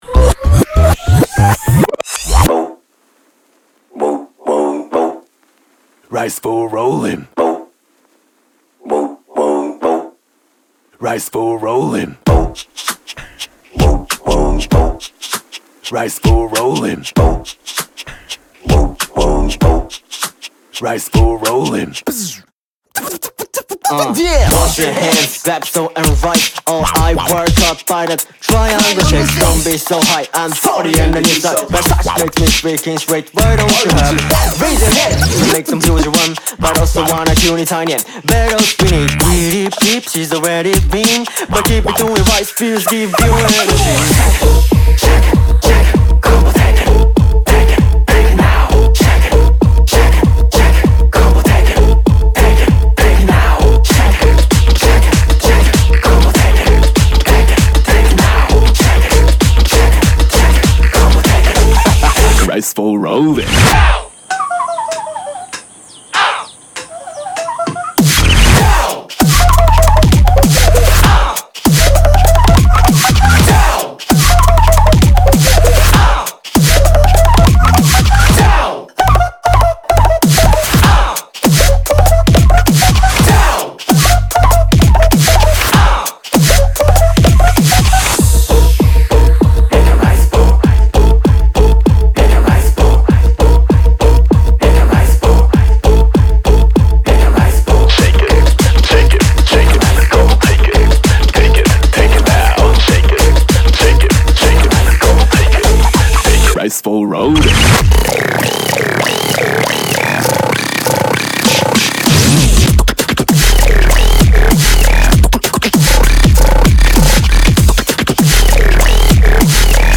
BPM98
Audio QualityCut From Video